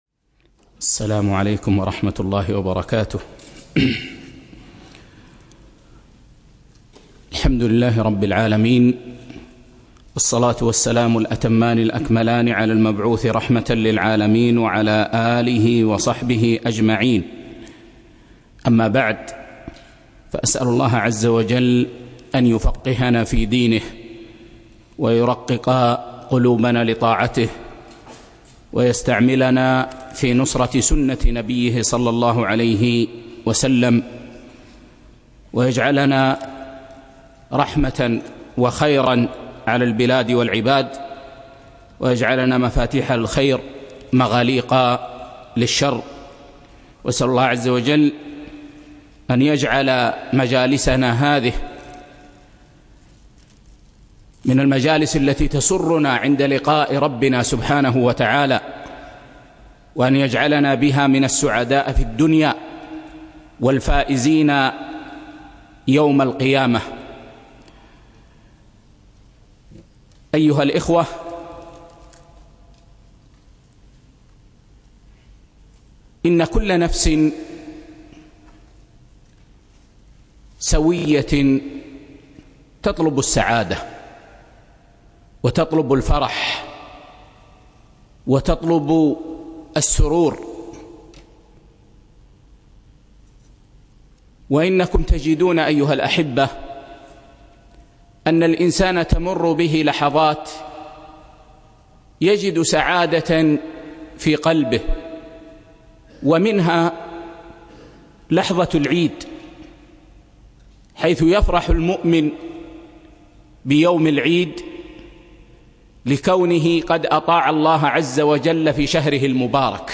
7- فقه المعاملات المالية (2) - الدرس الخامس